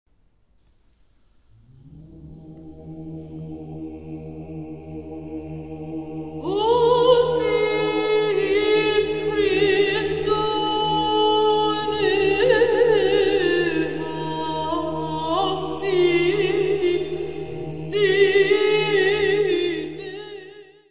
: stereo; 12 cm
Área:  Música Clássica